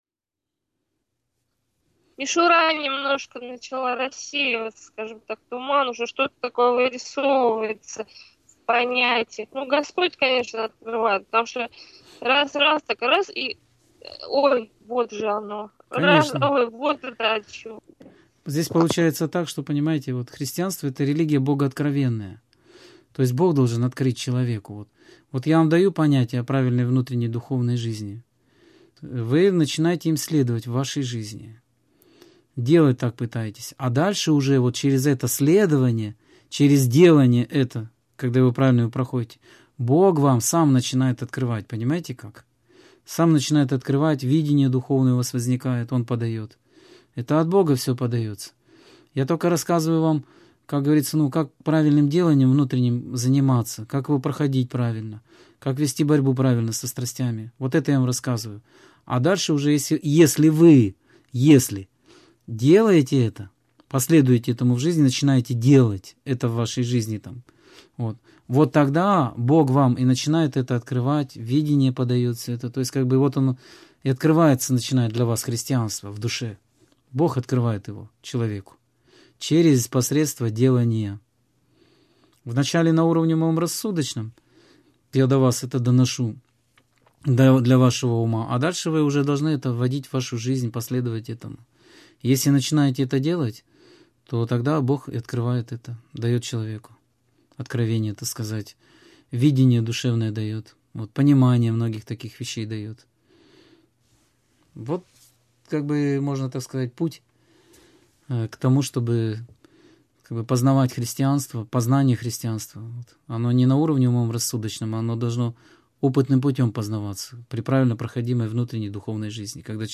Самоотвержение, немощь и смирение (Скайп-беседа 27.09.2014) — ХРИСТИАНСКАЯ ЦЕРКОВЬ